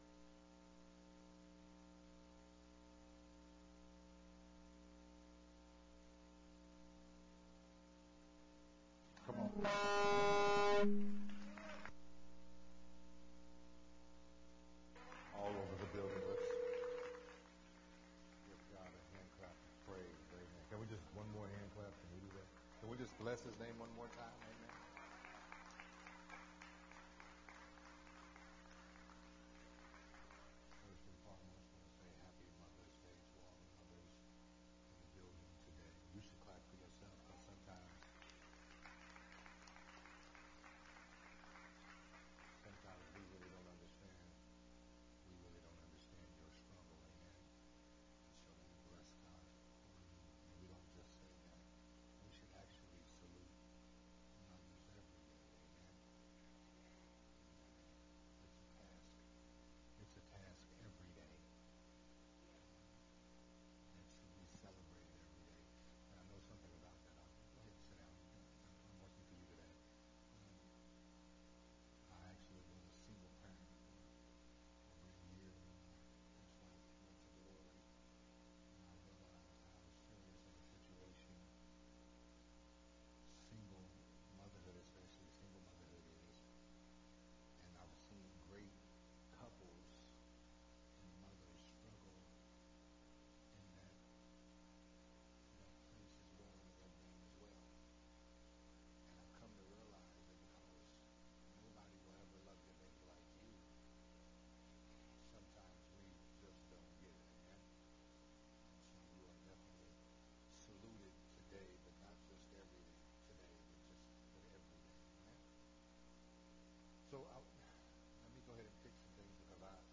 a special Mother’s Day message
recorded at Unity Worship Center on May 8th, 2022.